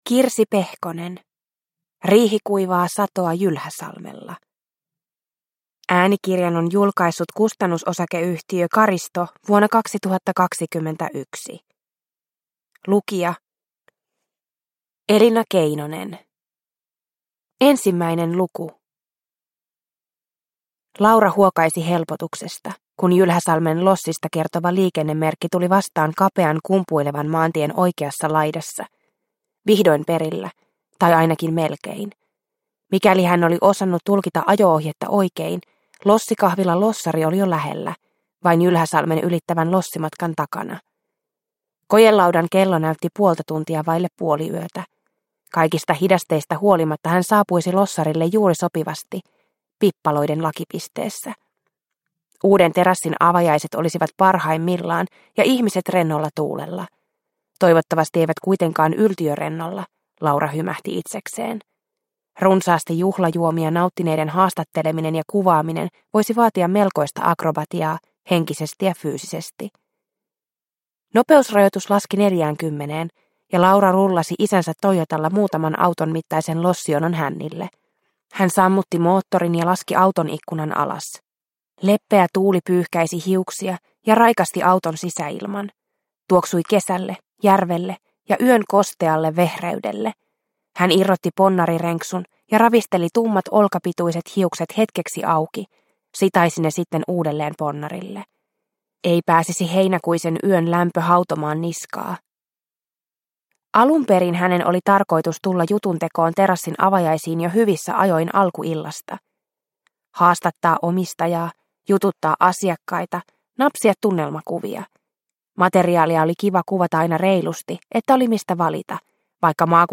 Riihikuivaa satoa Jylhäsalmella – Ljudbok – Laddas ner